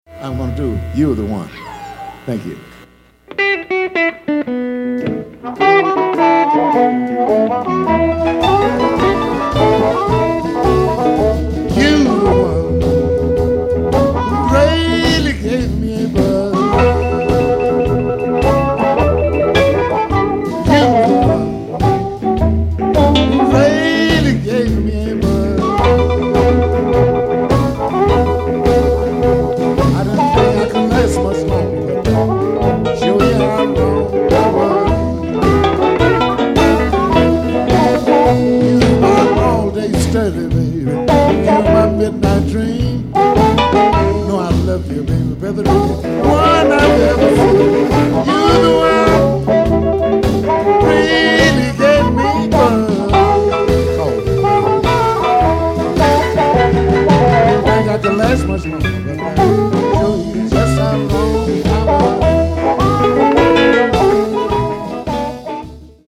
Recorded in the 1980's and 1990's